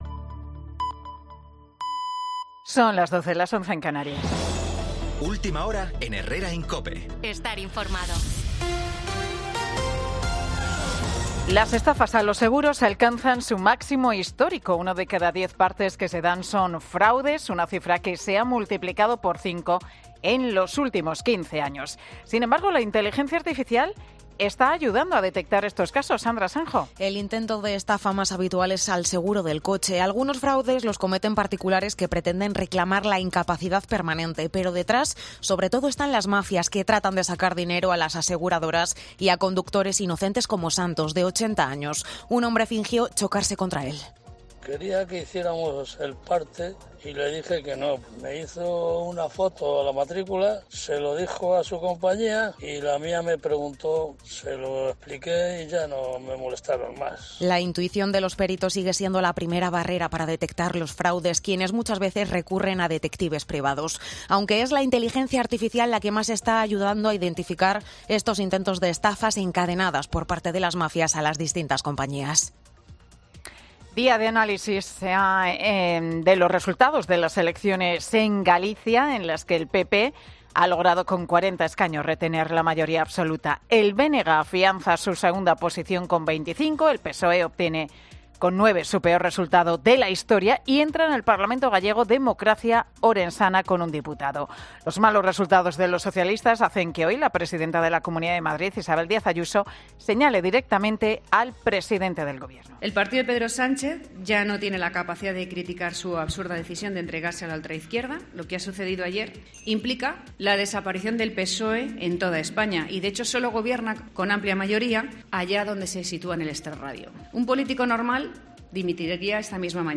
Boletín de Noticias de COPE del 19 de febrero del 2024 a las 12 horas